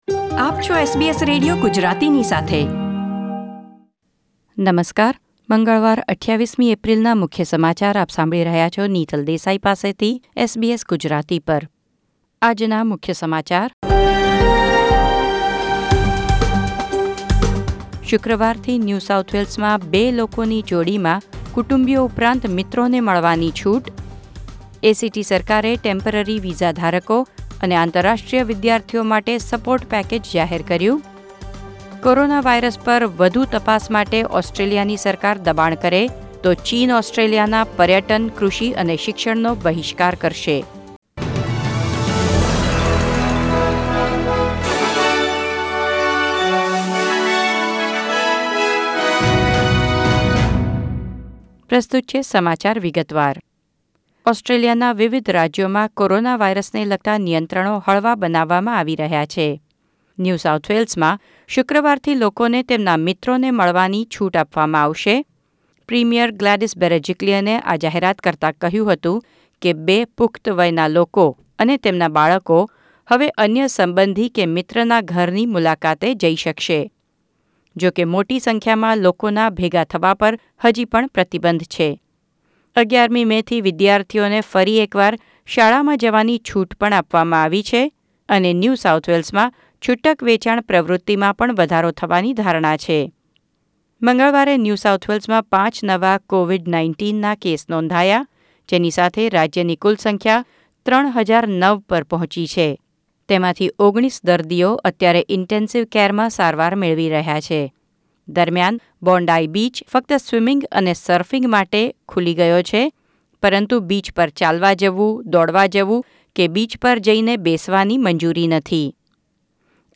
SBS Gujarati News Bulletin 28 April 2020